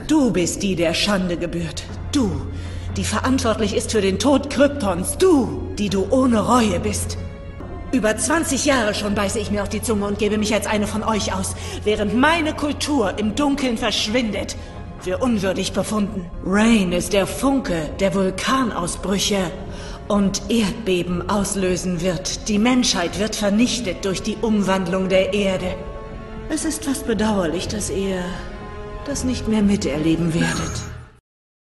Supergirl (Mittlere Rolle) - bedrohlich